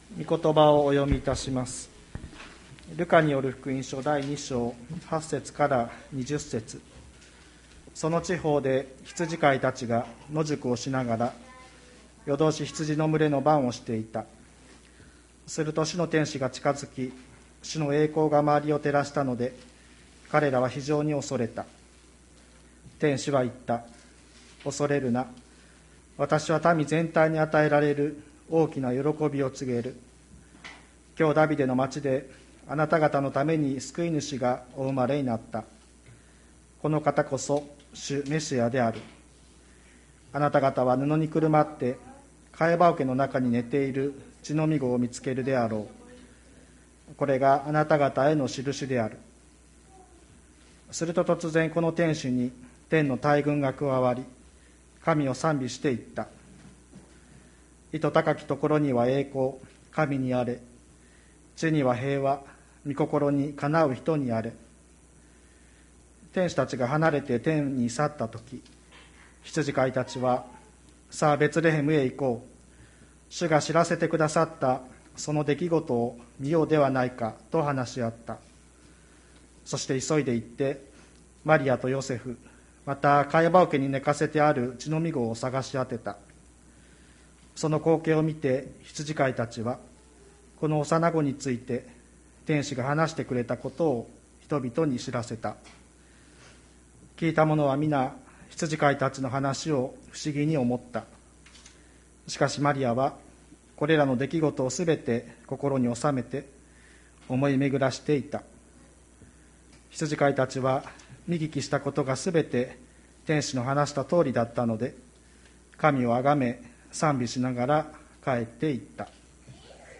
2020年12月27日朝の礼拝「静かに思い巡らすクリスマス」吹田市千里山のキリスト教会
千里山教会 2020年12月27日の礼拝メッセージ。